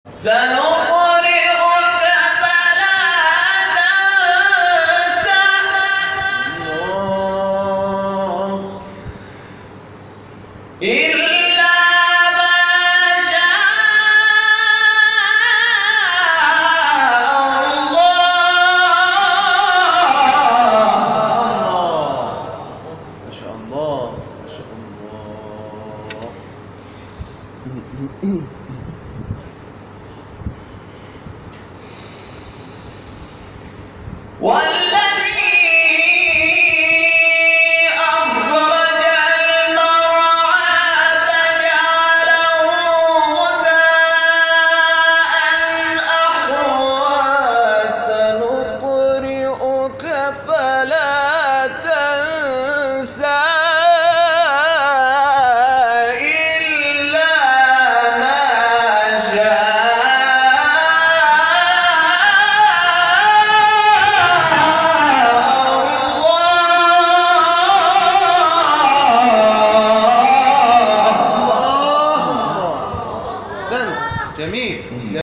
گروه شبکه اجتماعی: نغمات صوتی از تلاوت قاریان بین‌المللی و ممتاز کشور که به تازگی در شبکه‌های اجتماعی منتشر شده است، می‌شنوید.
سوره اعلی در مقام سگاه